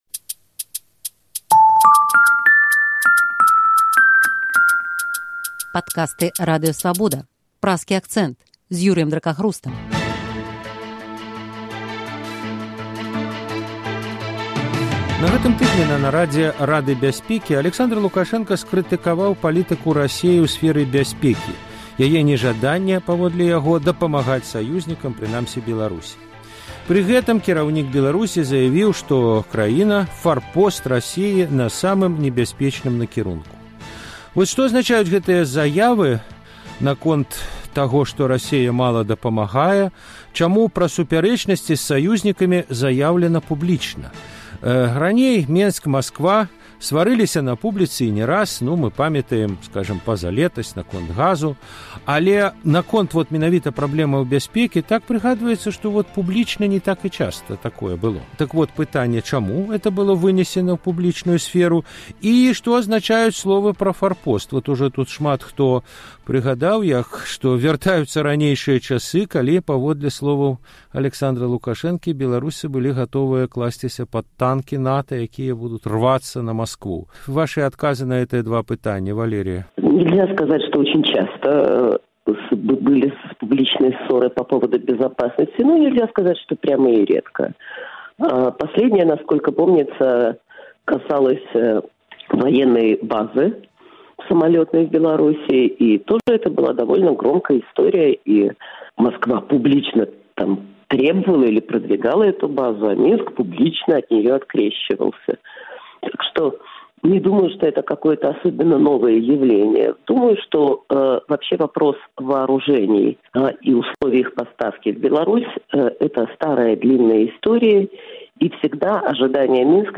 палітолягі